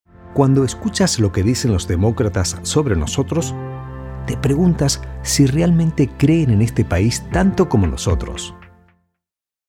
Spanish-speaking male voice actor
Spanish-Speaking Men, Political